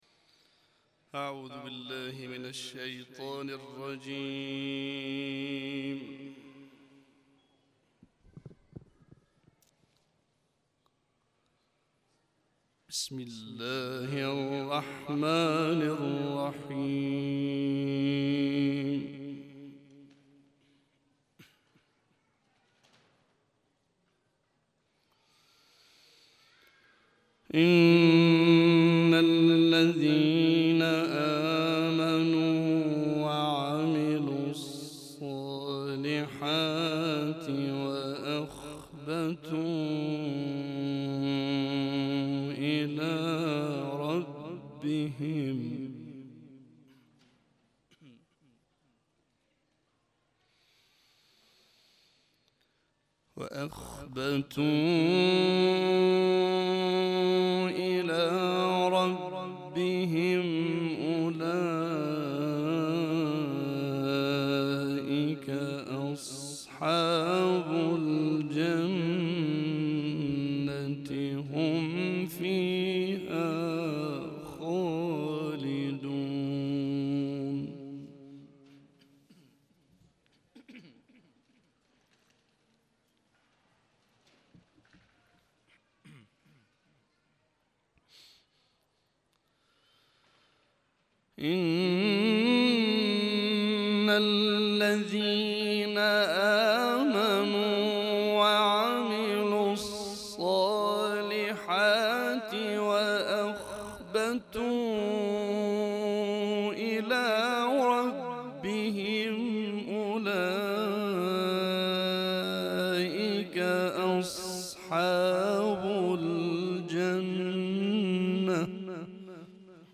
تلاوة الأستاذ في حرم الإمام الرضا (ع) 12-ربيع الثاني-1433 - لحفظ الملف في مجلد خاص اضغط بالزر الأيمن هنا ثم اختر (حفظ الهدف باسم - Save Target As) واختر المكان المناسب